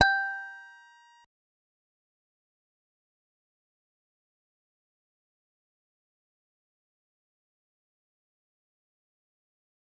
G_Musicbox-G6-mf.wav